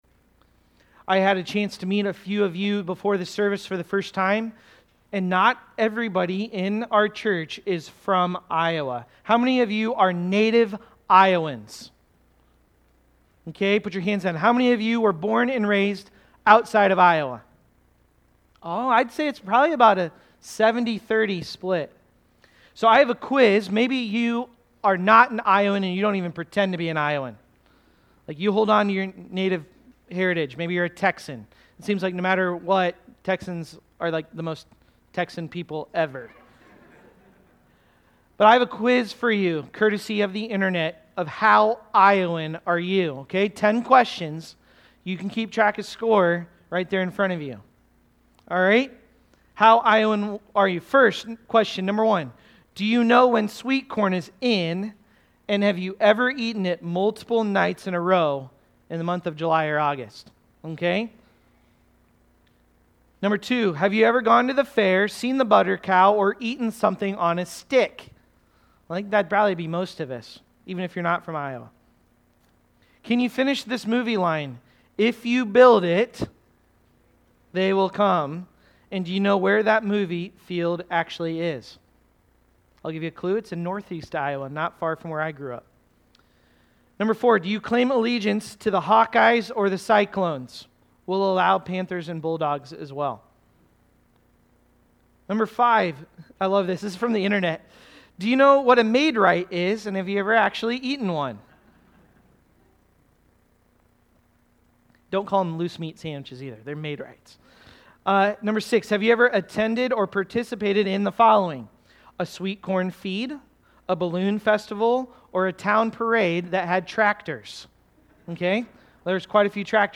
Always-Fighting-Always-Delighting-Sermon-Audio.mp3